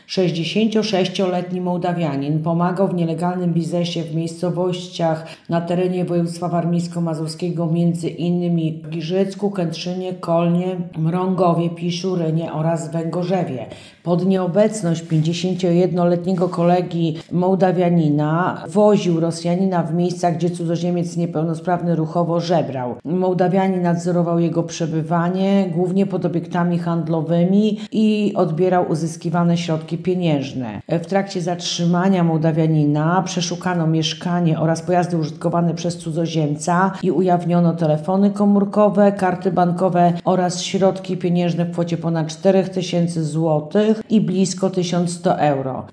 Mówiła Radiu 5